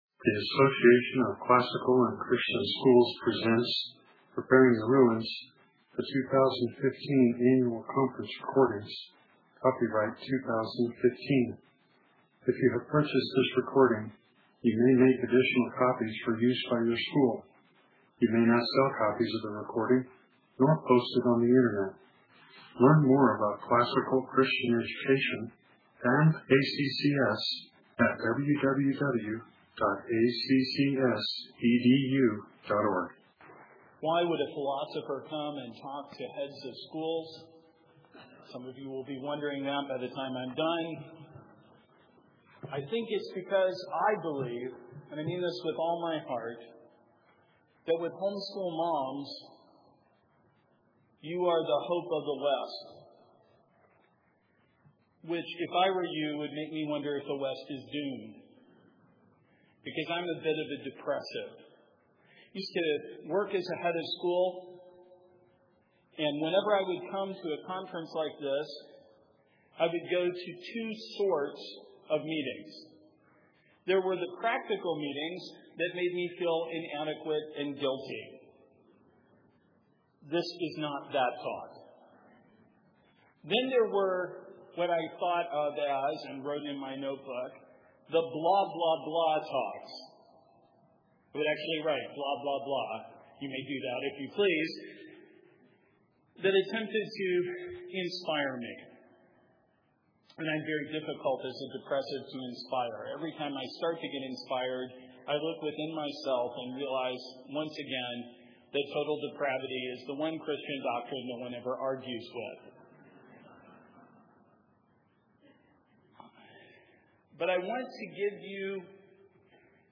2015 Workshop Talk | 1:04:04 | Leadership & Strategic